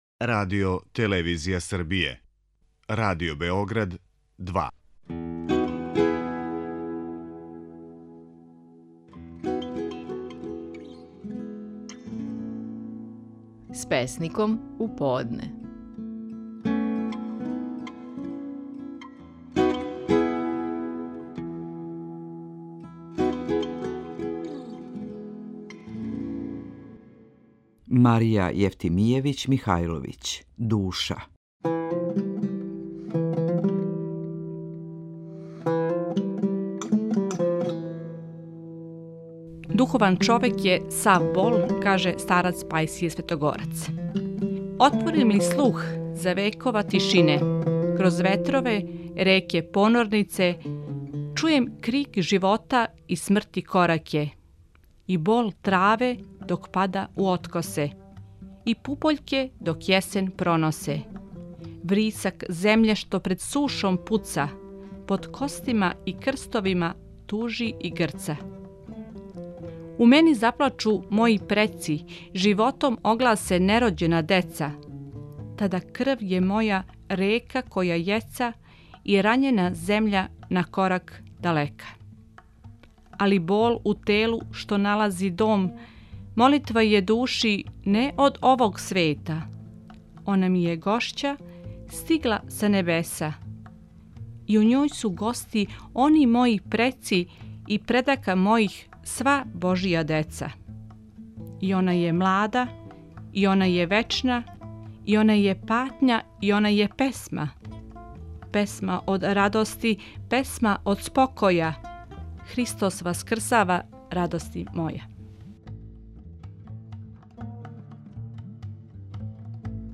Стихови наших најпознатијих песника, у интерпретацији аутора